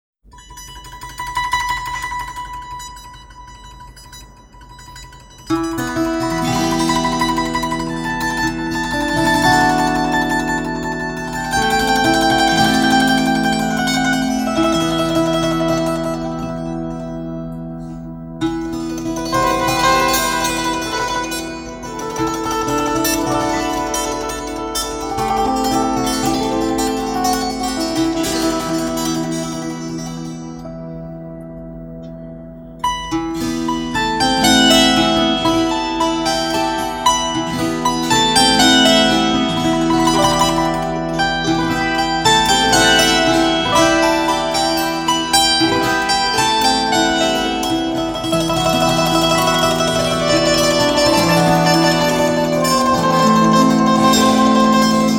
академски образован музичар који је извођач руског народног епоса и народних духовних песама и балада уз традиционалнe жичане инструменте: гусле (подсећа на цитру) и каљоснују лиру (налик на харди гарди)
руске епске и духовне песме